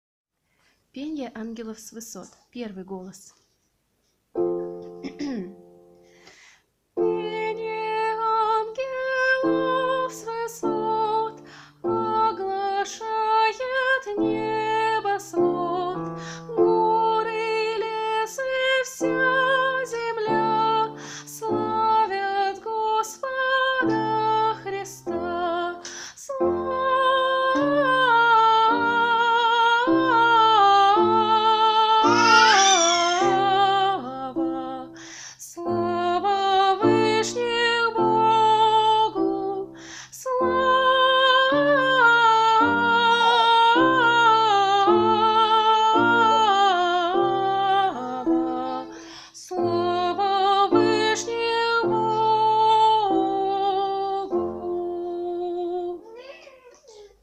Singing Angels from the Heights - 1 voice 41135
• Category: Sounds of god and angel